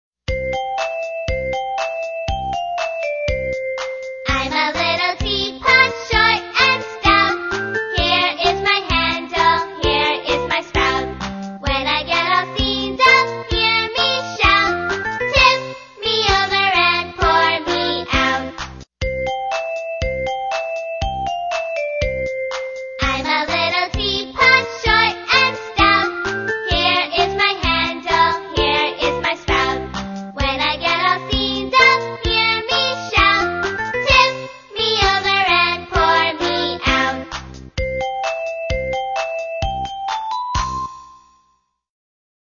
在线英语听力室英语儿歌274首 第91期:I'm a Little Teapot(2)的听力文件下载,收录了274首发音地道纯正，音乐节奏活泼动人的英文儿歌，从小培养对英语的爱好，为以后萌娃学习更多的英语知识，打下坚实的基础。